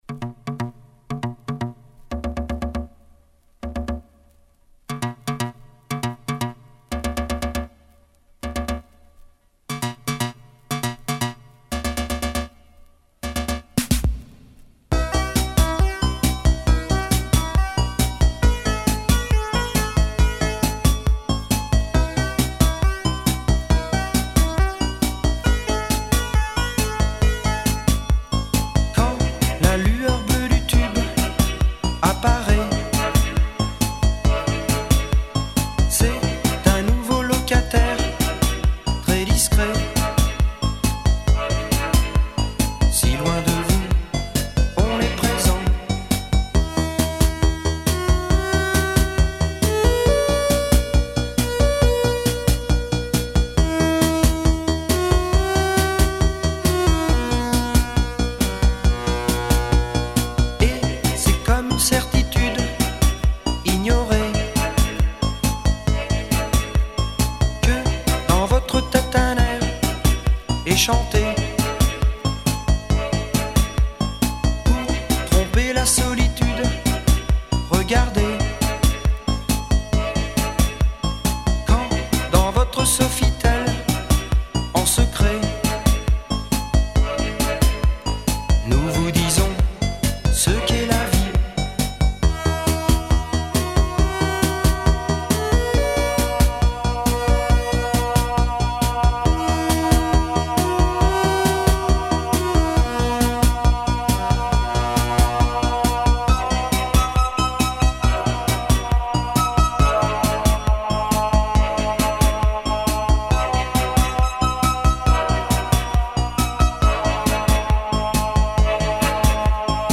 Filed under coldwave, paris